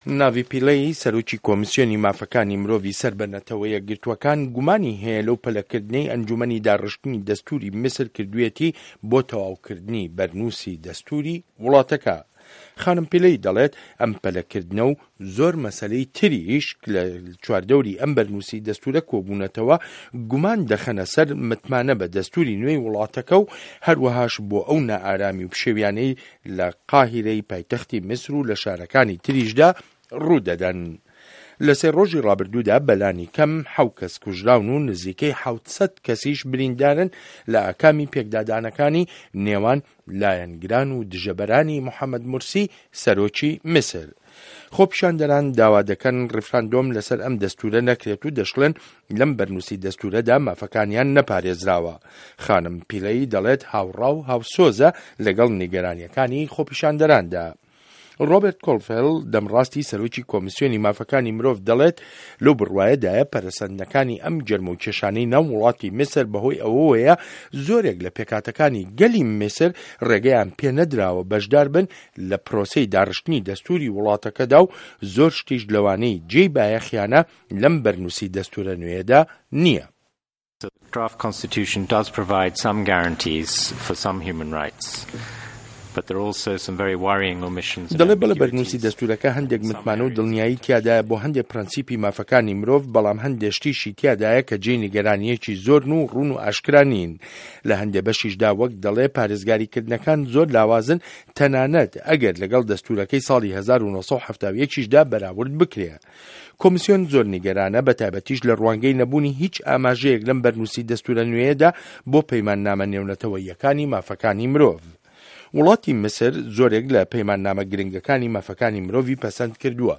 ڕاپۆرتی ده‌نگی ئه‌مه‌ریکا سه‌باره‌ت به‌ میسر